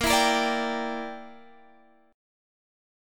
Listen to A#6b5 strummed